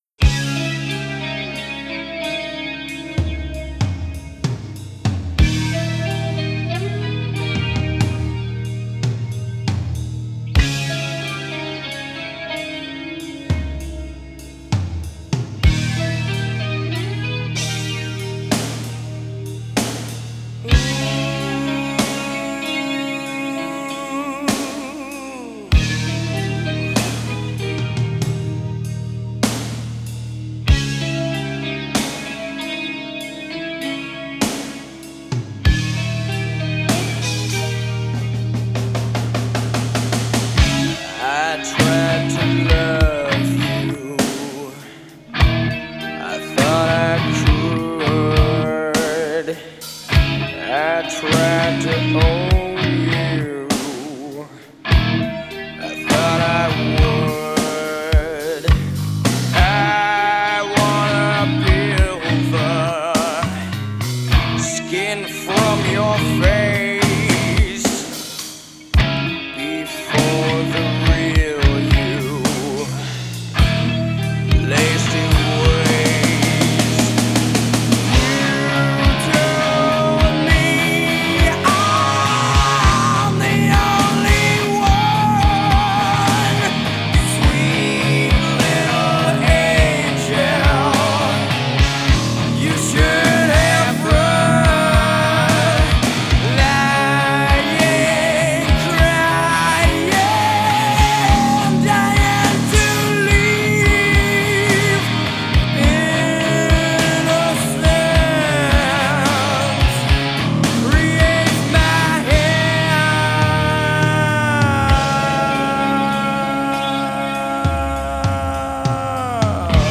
Alternative Rock
Hard Rock